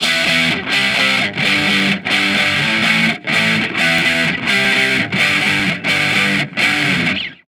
Guitar Licks 130BPM (17).wav